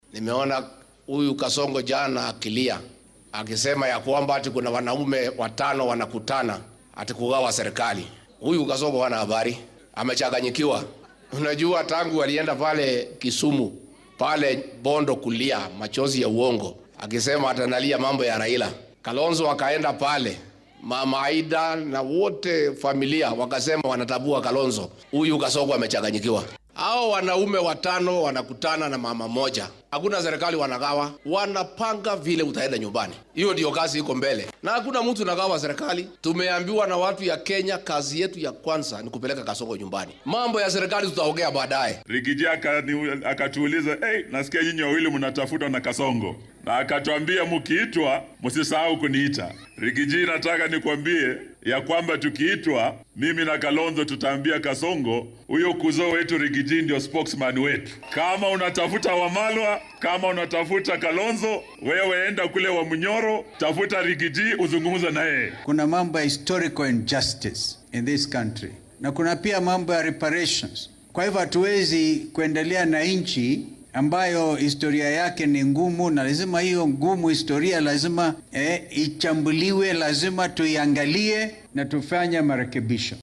Waxaa ay Talaadadii ka hadleen deegaanka Gilgil ee dowlad deegaanka Nakuru